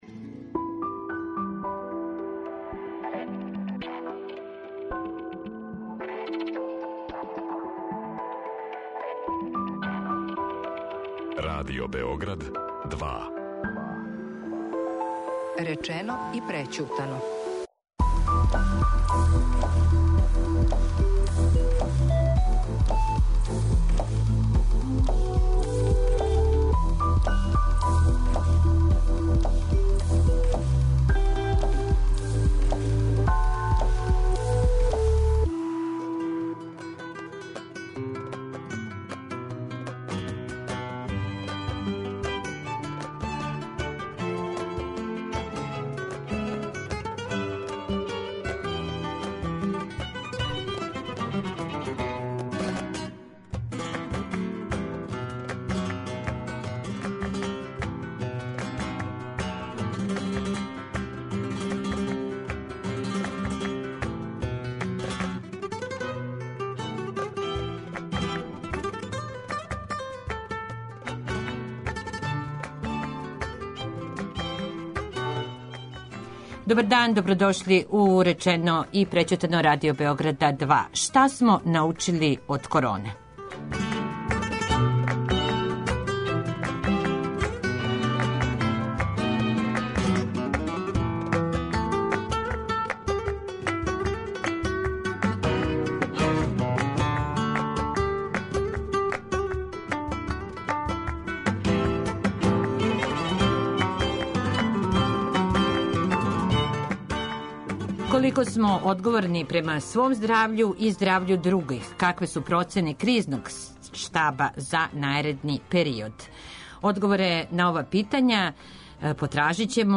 Одговоре на ова питања потражићемо у разговору са професором Бранимиром Несторовићем, чланом Кризног штаба за борбу против вируса корона.